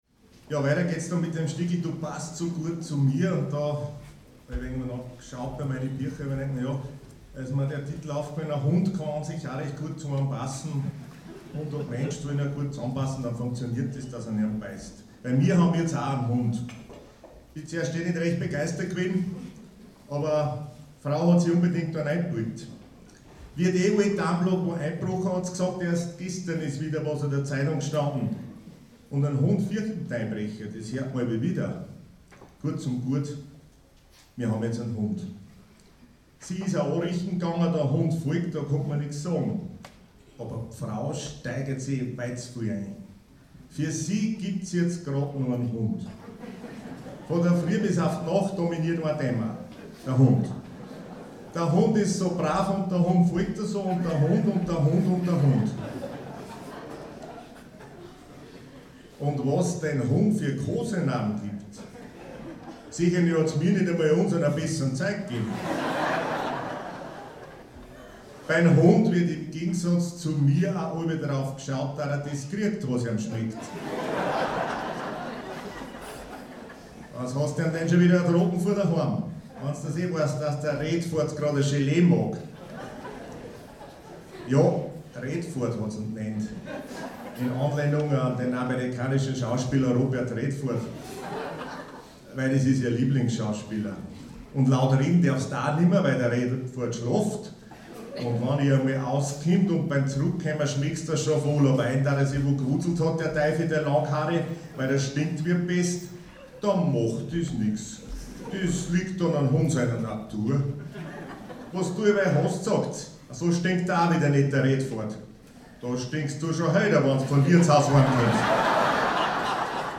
24.06.2017 - Konzert - SOMMERCOCKTAIL